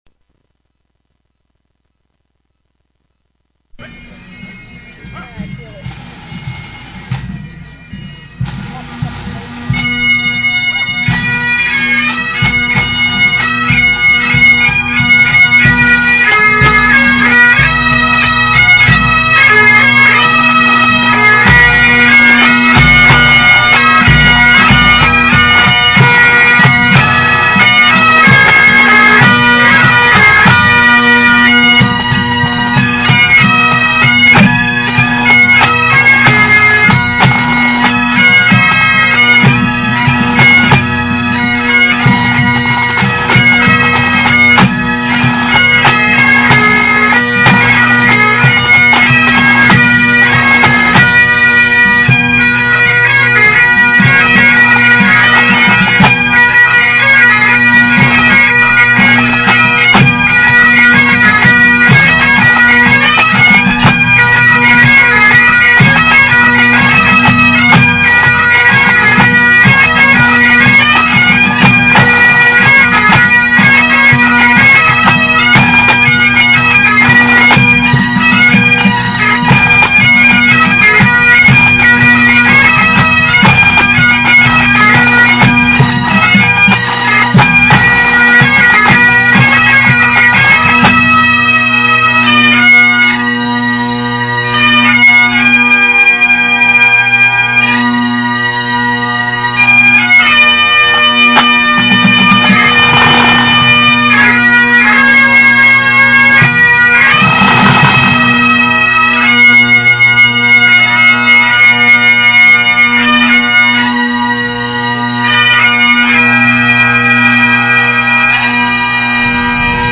Click here to listen to the performance which earned us the title of 2002 North American Pipe Band Grade IV Champions in Maxville, Ontario.)
Maxville GrIV perf.mp3